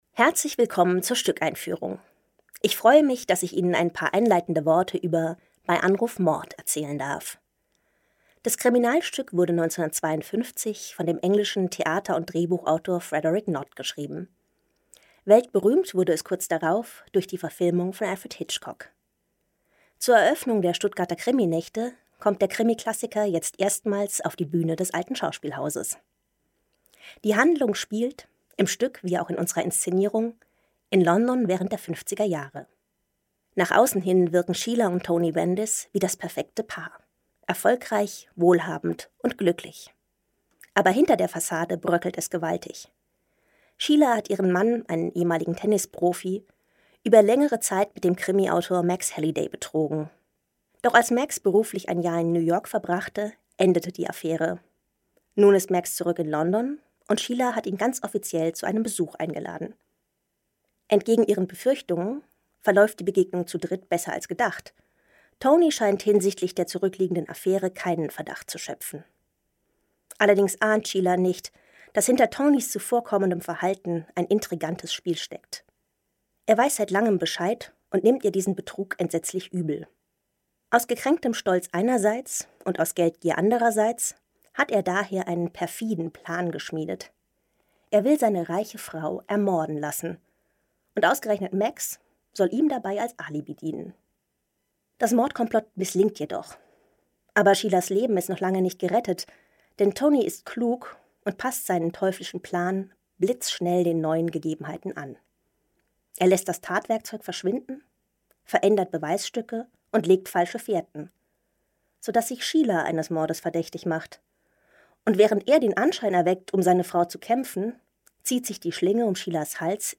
Stückeinführung